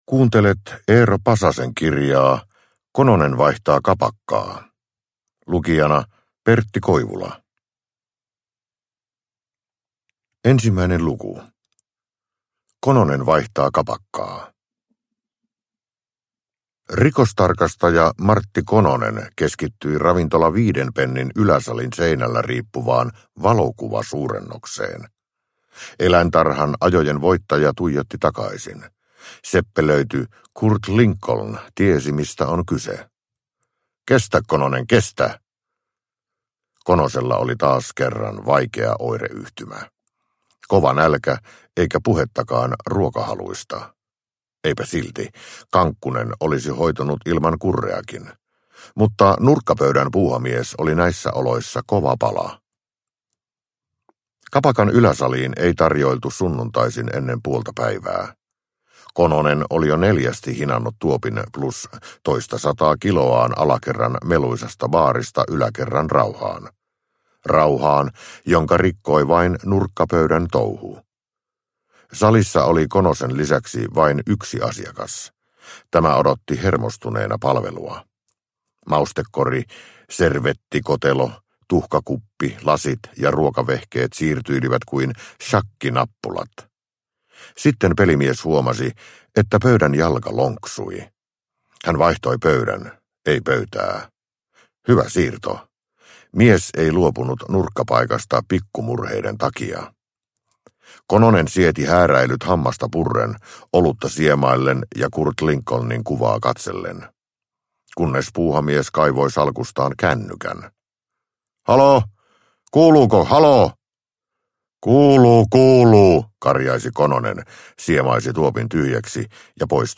Uppläsare: Pertti Koivula